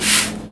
R - Foley 75.wav